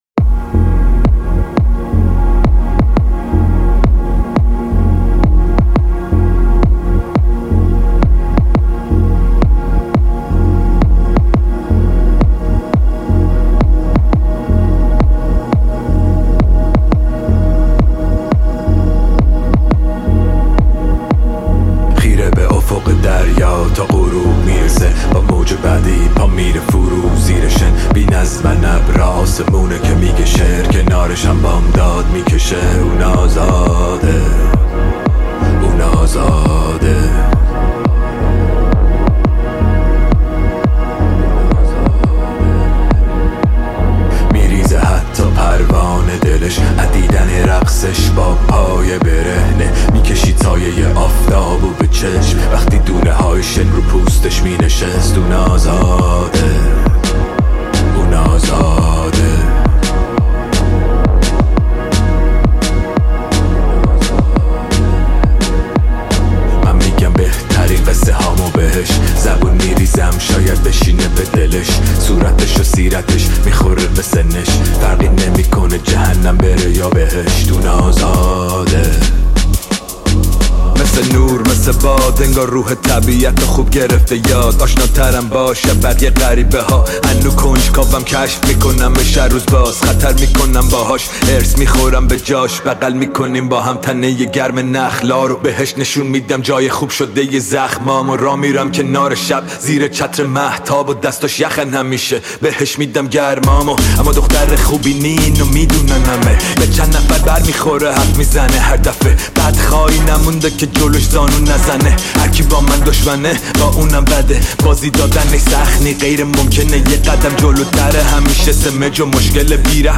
موسیقی، فضایی سینمایی، حماسی و در عین حال شخصی ایجاد می‌کند.